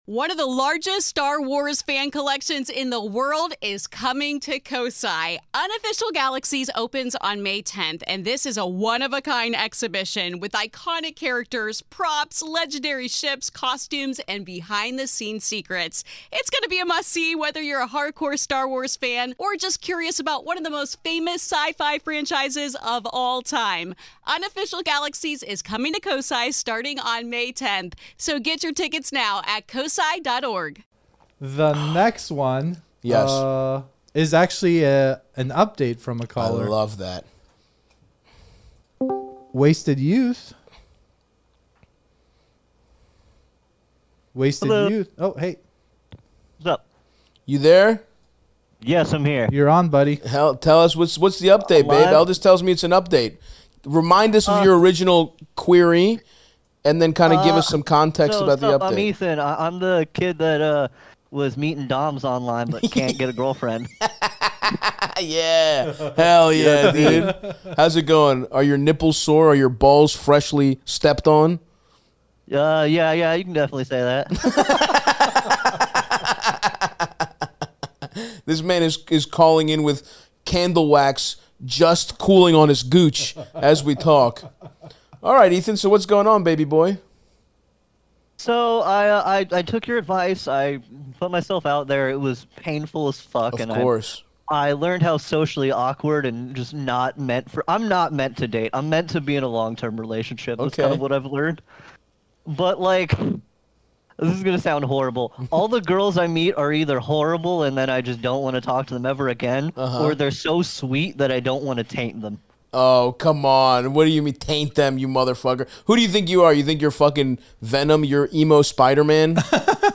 Stav speaks directly to callers in the very special, first ever Live Call episode of Stavvy's World!! Stav loses his shirt and gets extra comfy on the couch to mock callers in real time, and offer advice on the daunting life challenges they face.